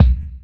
Kicks
Kick (5).wav